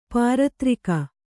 ♪ pāratrika